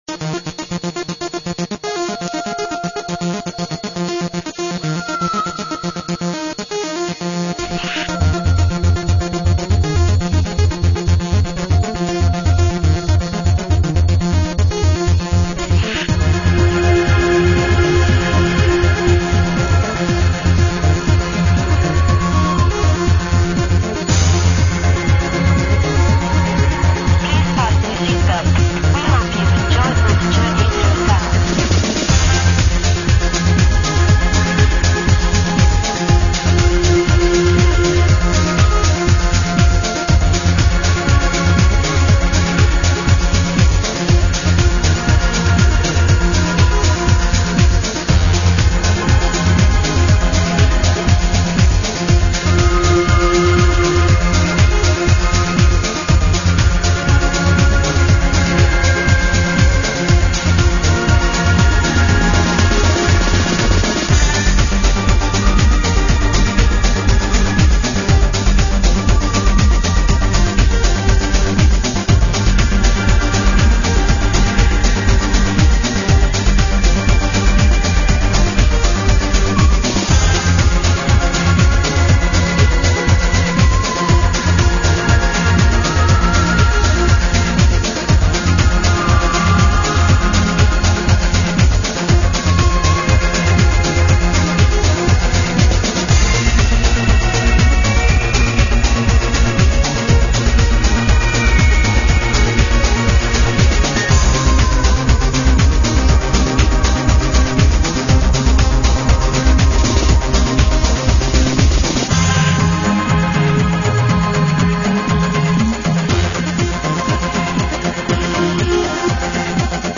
dance/electronic
House